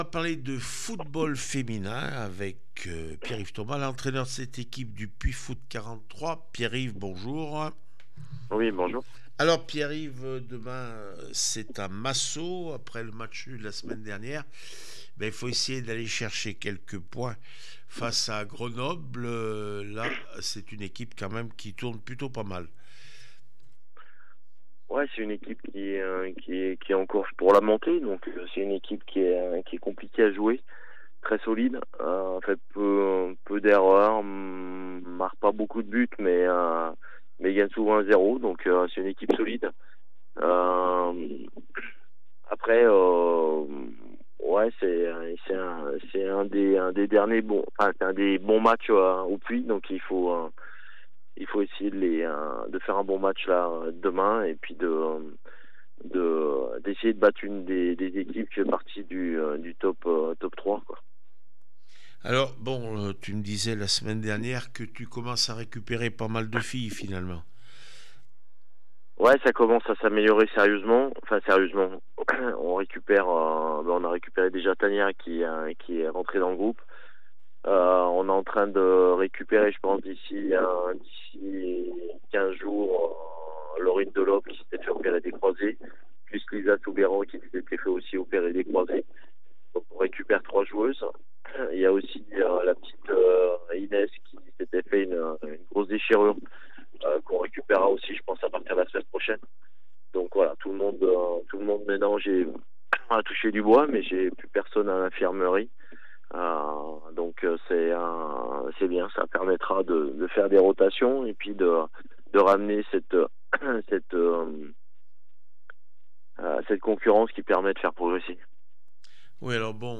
8 mars 2025   1 - Sport, 1 - Vos interviews